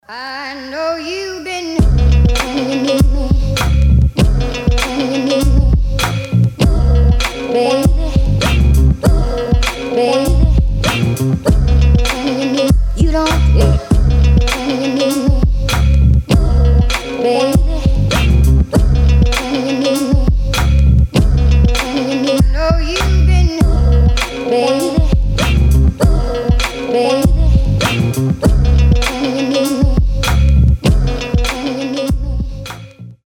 ритмика на звонок